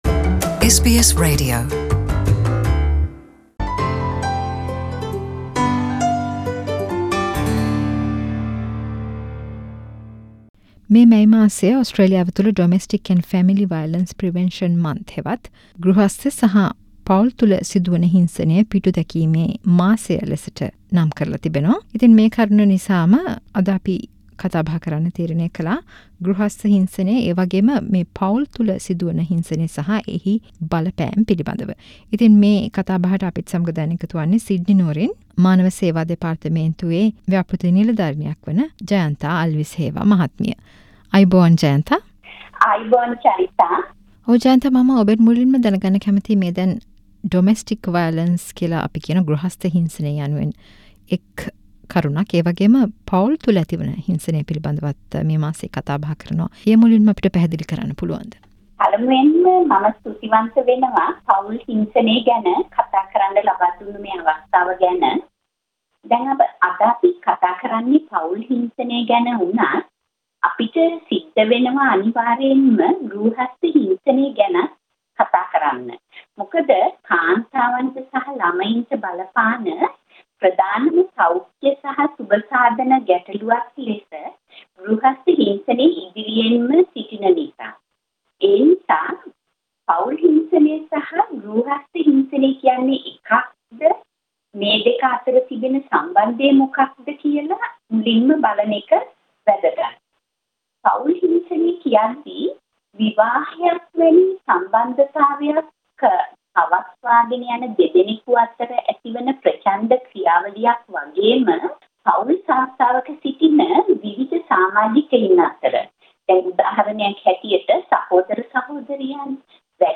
කතා බහක්